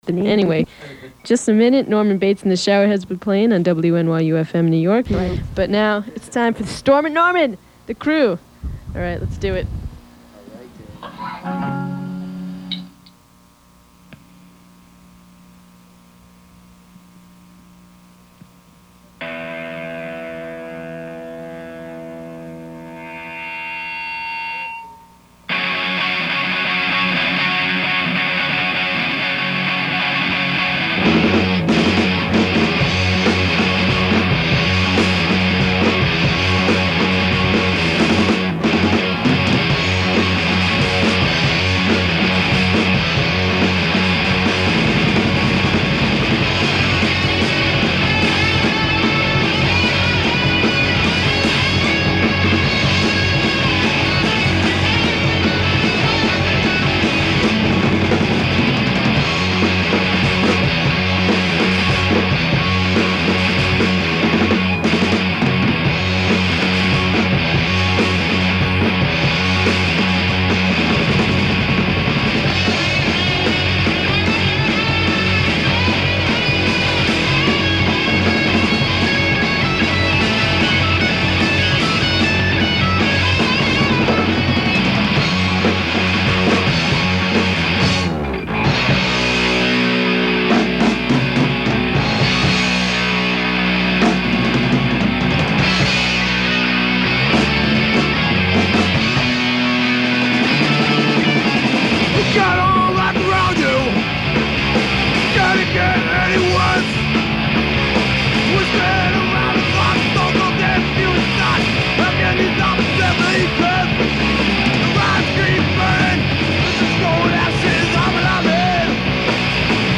NY Hardcore